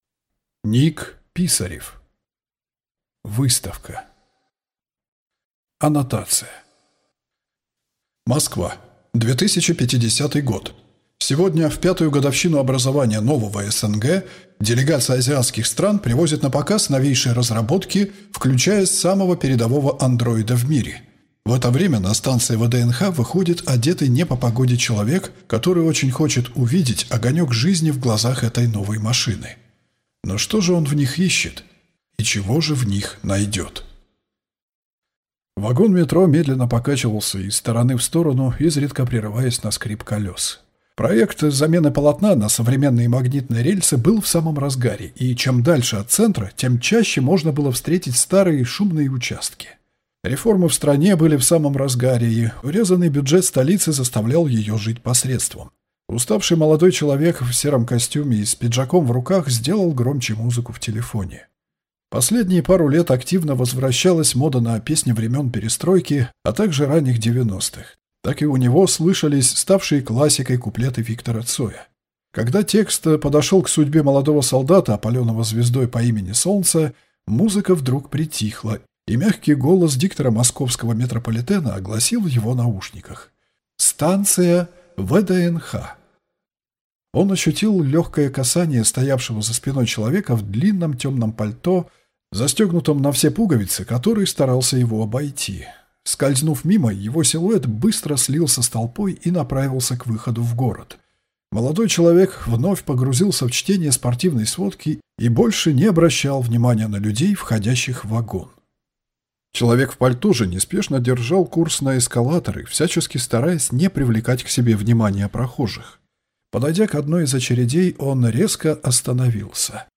Аудиокнига Выставка | Библиотека аудиокниг